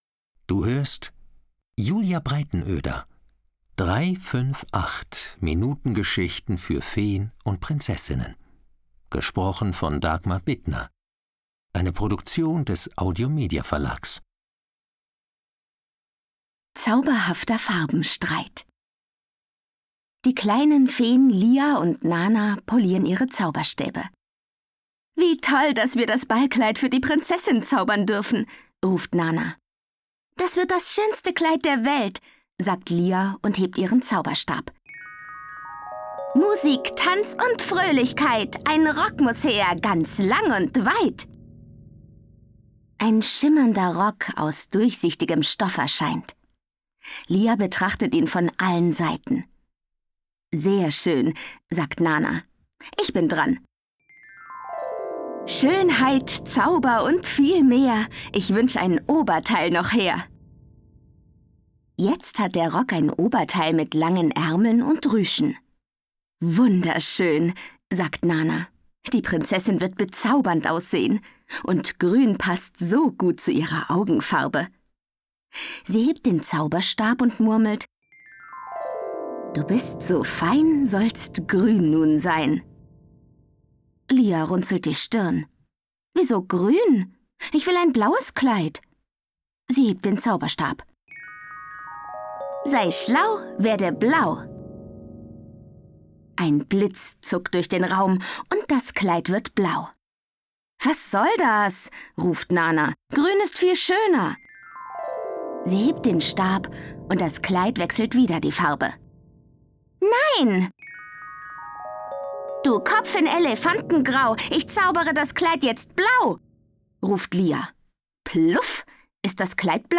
Kinder- / Jugendbuch Vorlesebücher / Märchen